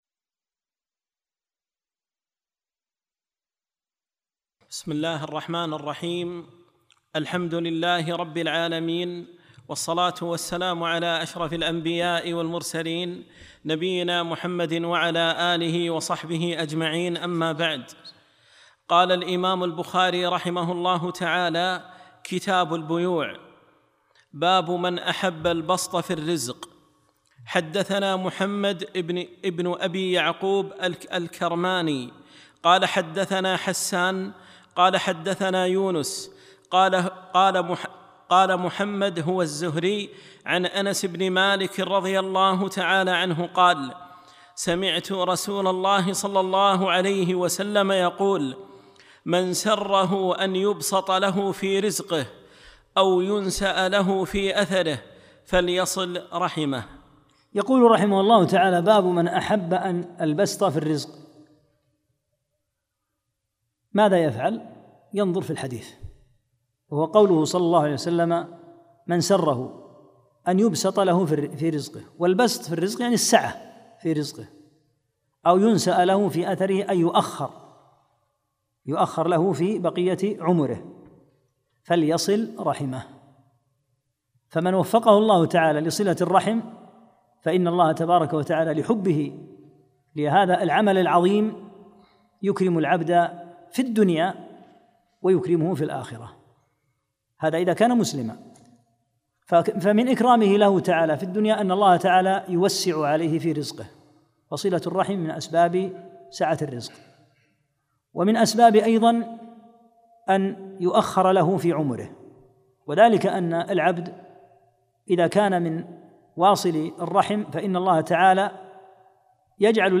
3- الدرس الثالث